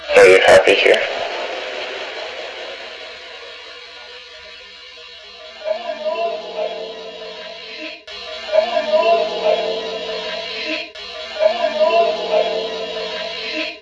A second spirit voice can be heard as well, speaking over the first lower voice. This spirit screams out something that sounds like "Get them off me!"
creepy voices (With noise reduction applied & looped x3 )